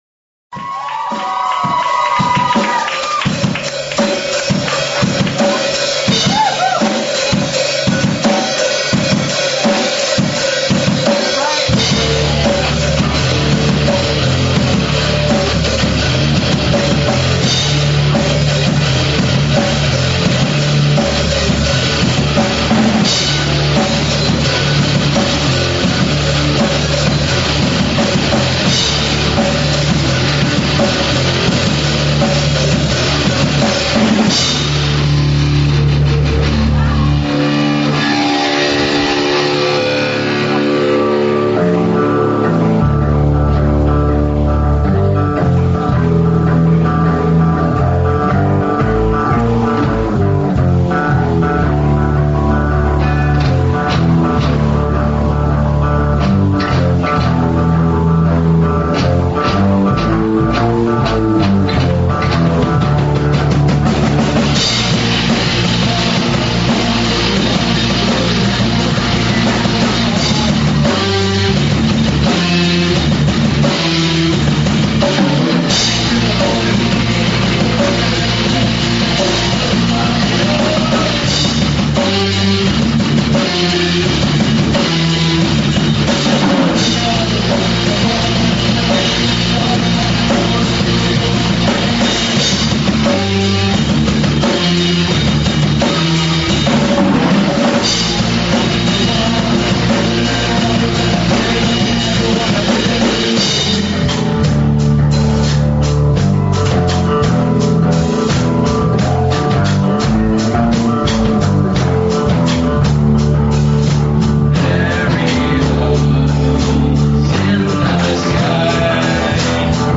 AUDIO OF YOUTH CHURCH BAND, REBORN AUDIO OF GROUND BREAKING CEREMONY FOR NEW CHURCH BUILDINGWindows Media Player Required